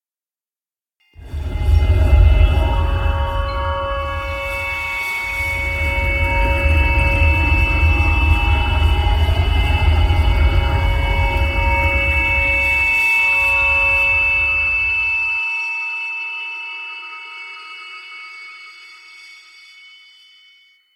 Tinnitus_03.ogg